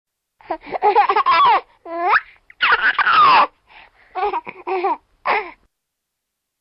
婴儿笑声音效_人物音效音效配乐_免费素材下载_提案神器
婴儿笑声音效免费音频素材下载